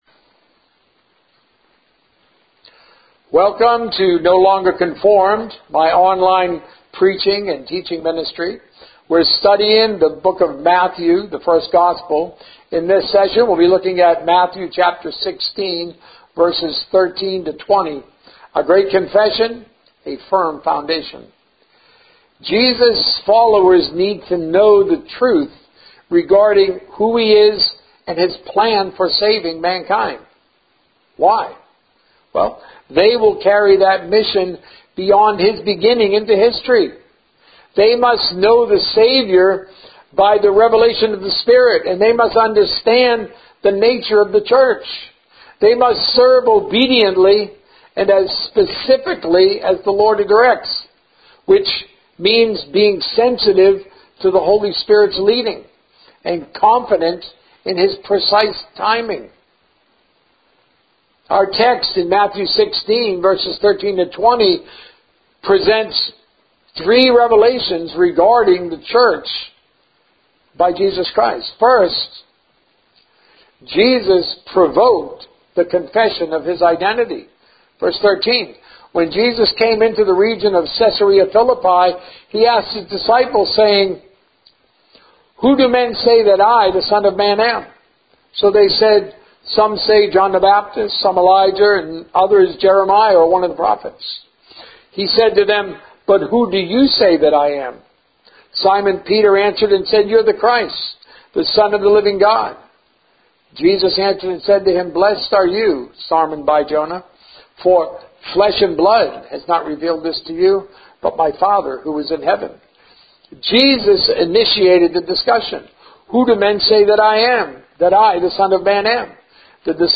A message from the series "The First Gospel."